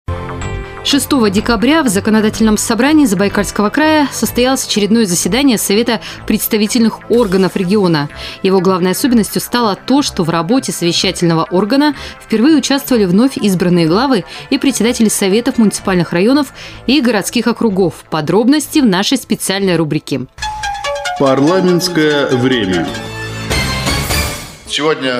Информационный аудиосюжет "Взаимодействие с районными коллегами".
"Радио России - Чита", эфир 12 декабря 2012 года.